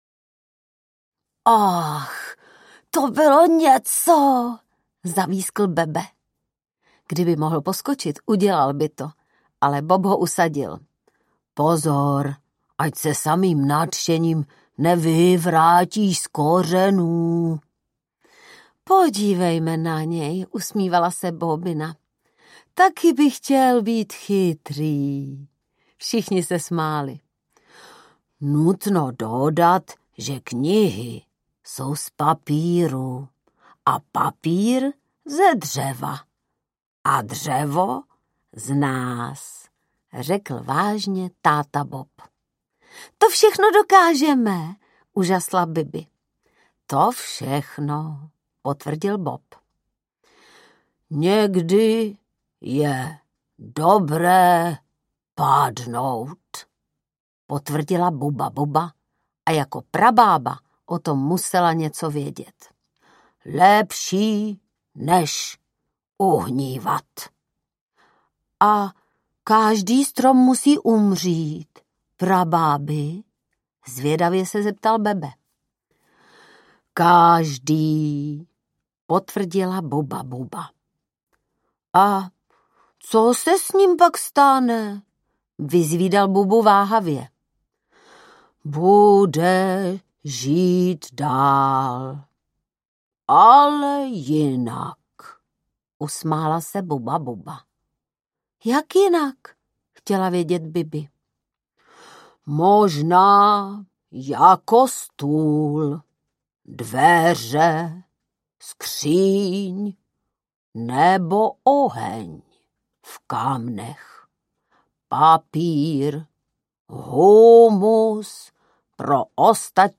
Stromy, kořeny a kluk audiokniha
Ukázka z knihy
Vyrobilo studio Soundguru.